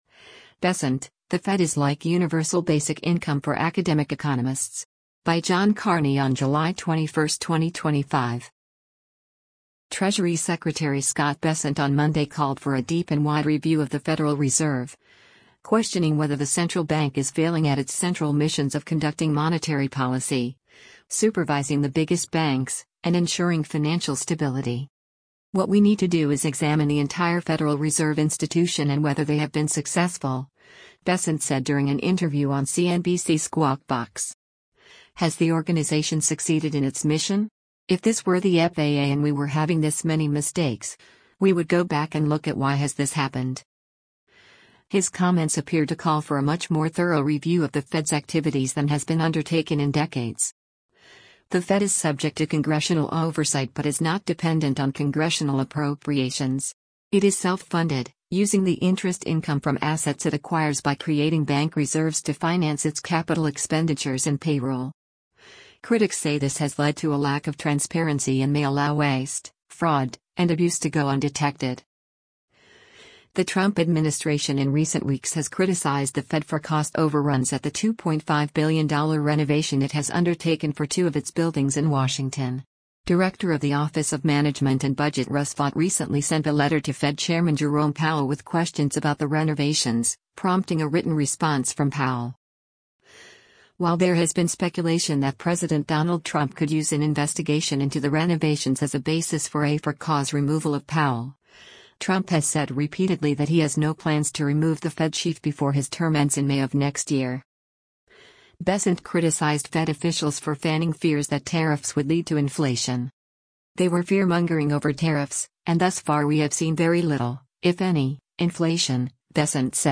“What we need to do is examine the entire Federal Reserve institution and whether they have been successful,” Bessent said during an interview on CNBC’s “Squawk Box.”